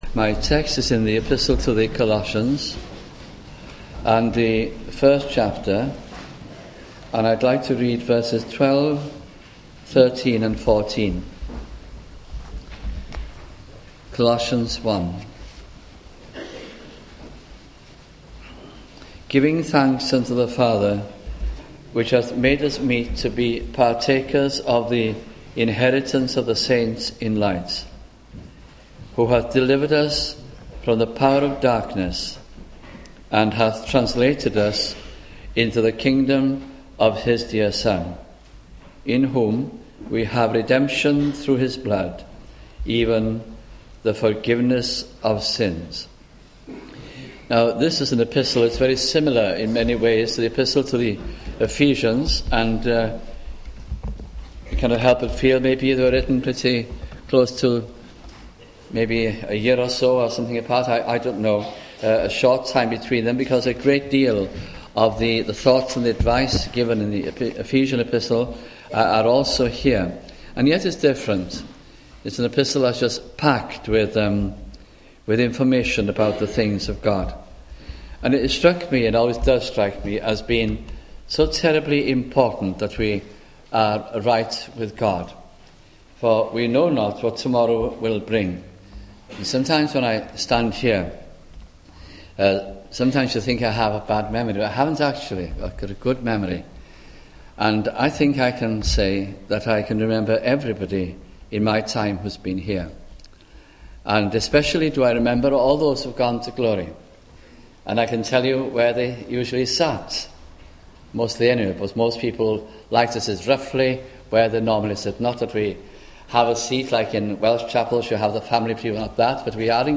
» Colossians Gospel Sermons